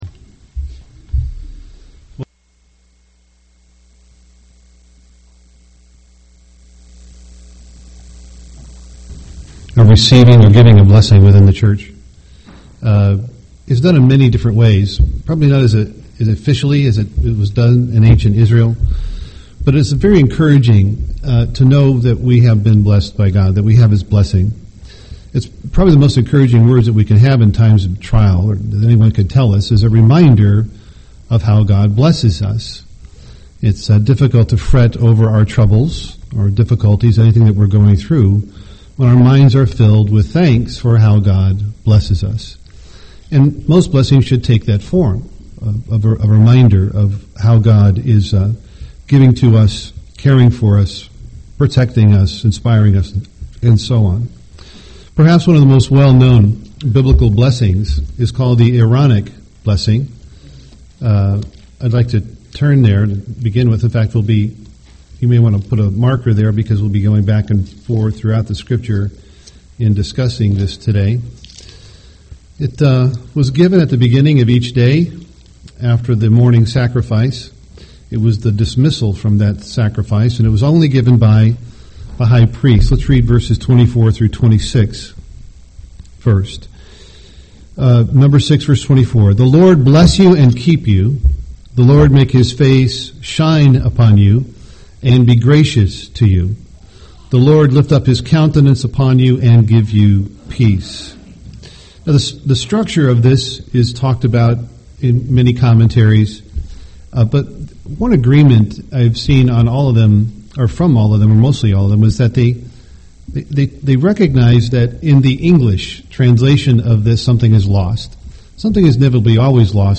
UCG Sermon Blessings Studying the bible?